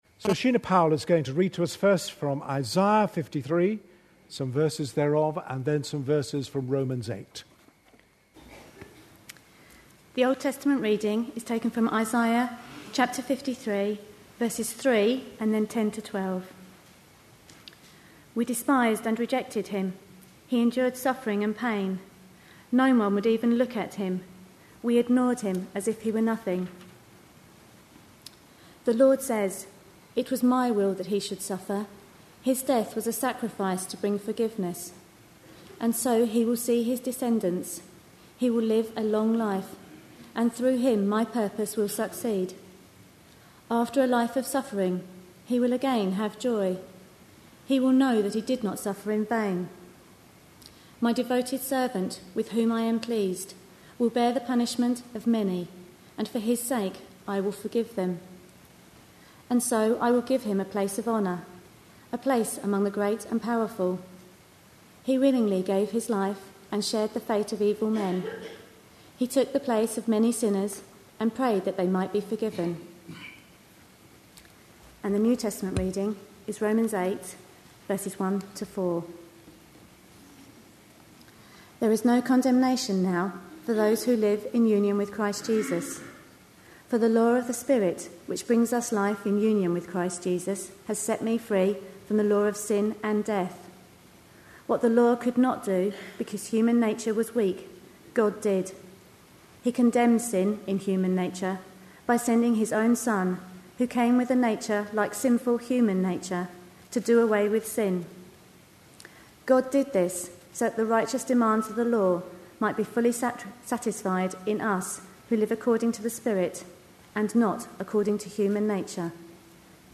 A sermon preached on 22nd April, 2012, as part of our Life in The Spirit series.